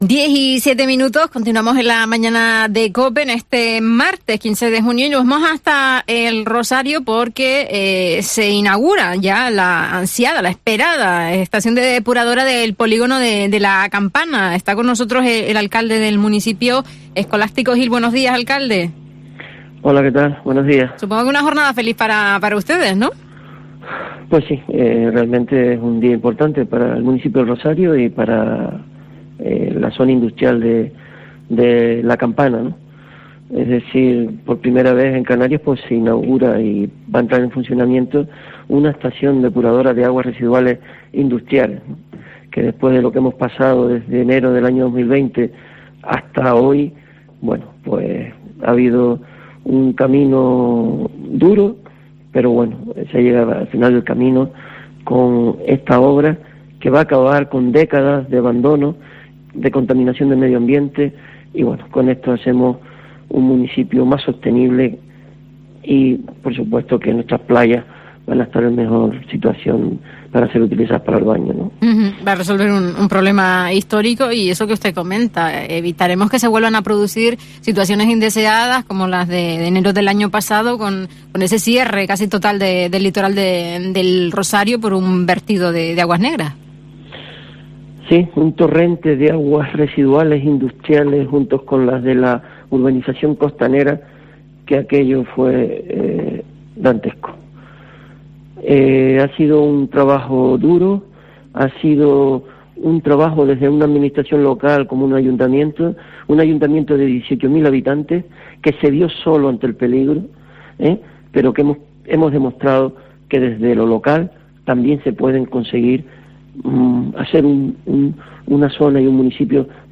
Escolástico Gil, alcalde de El Rosario, valora la inauguración de la depuradora de La Campana
El alcalde de El Rosario, Escolástico Gil, ha señalado en COPE Canarias que se trata de "un día importante" para el municipio, porque ha sido "un duro camino que llega al final para acabar con décadas de contaminación al medio ambiente".